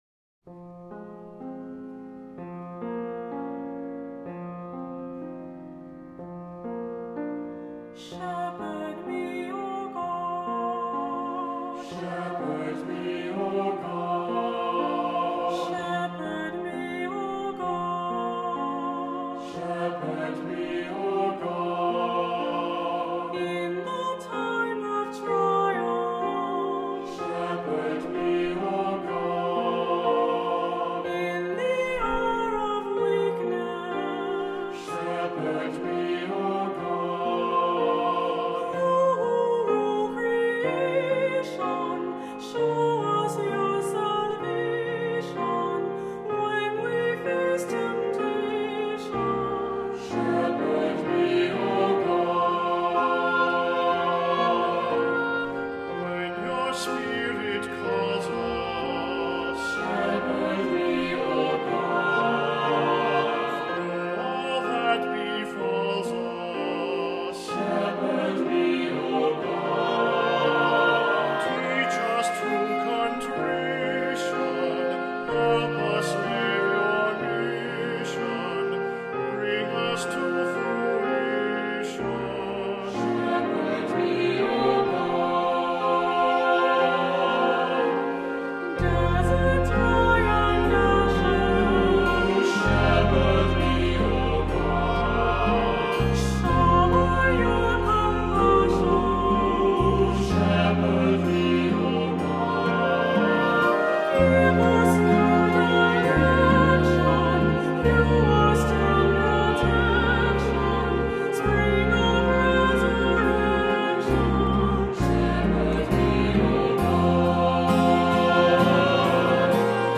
Voicing: Unison; SATB; Priest; Assembly